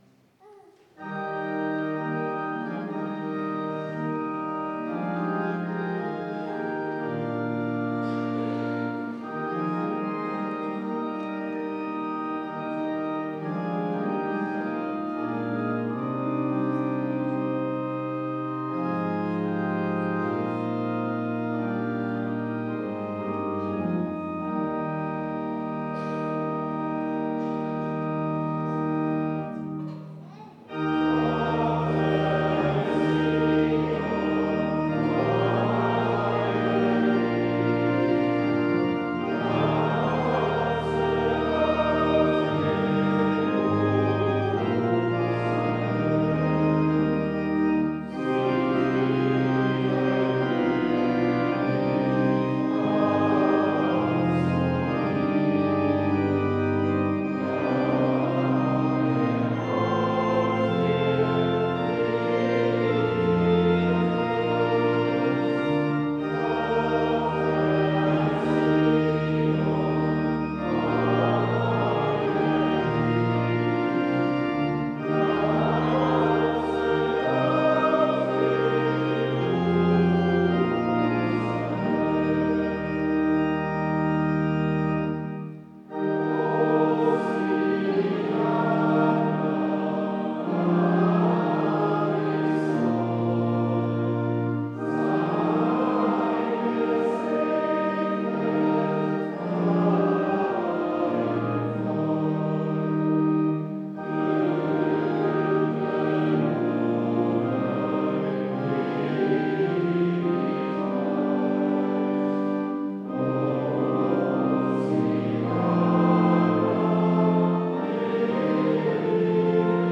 Audiomitschnitt unseres Gottesdienstes vom 4.Advent 2025.